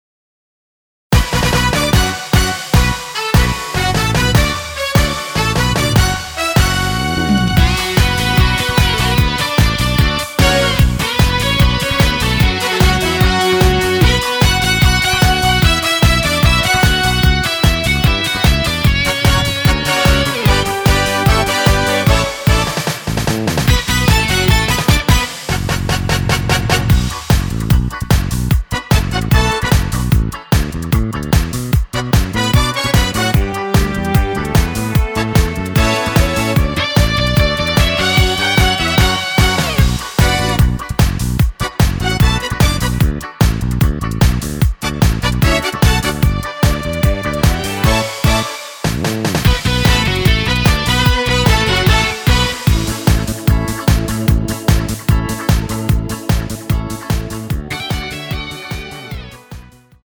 MR입니다.
Bm
앞부분30초, 뒷부분30초씩 편집해서 올려 드리고 있습니다.
중간에 음이 끈어지고 다시 나오는 이유는